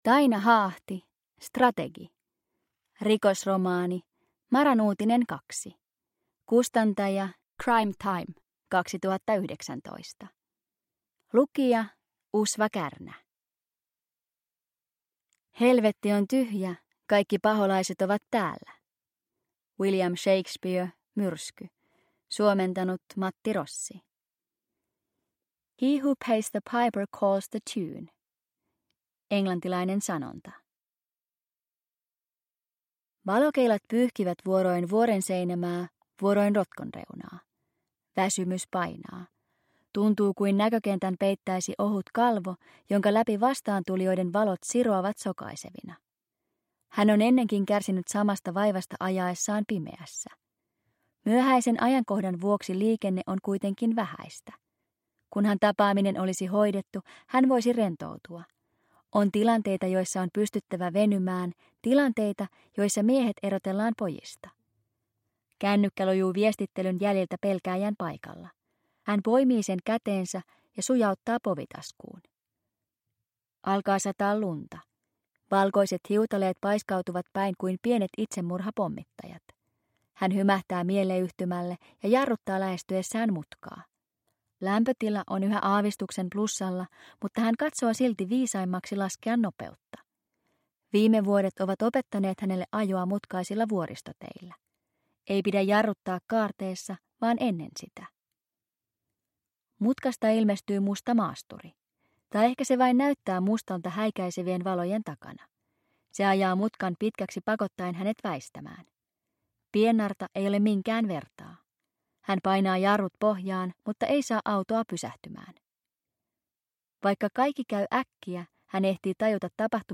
Strategi (ljudbok) av Taina Haahti